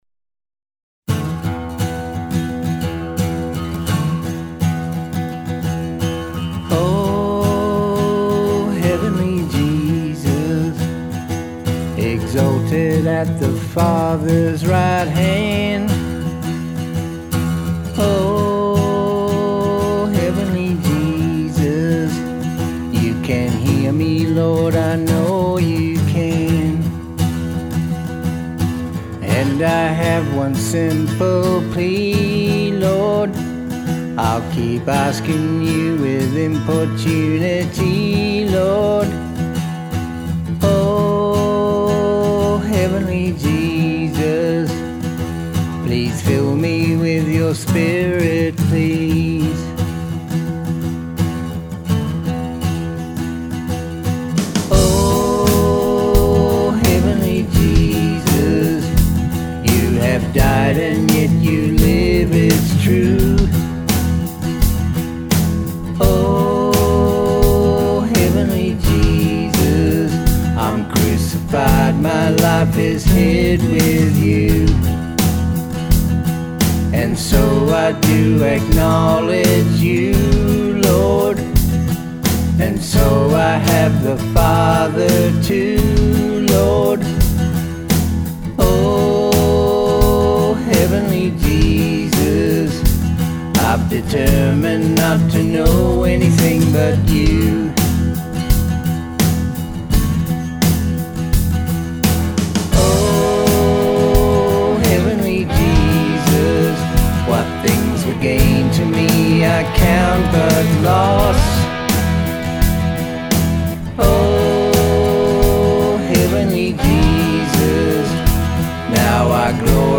Grace gospel songs